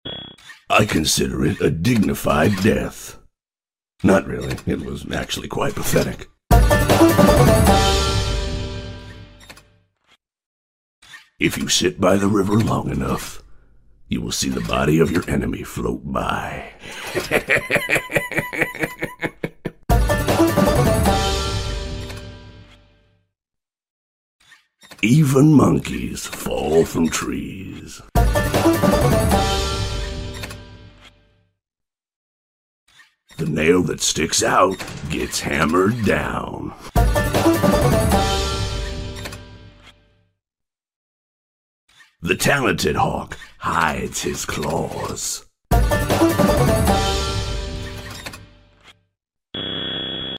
Pigpatch Voice Lines 🐷🐽| Parte sound effects free download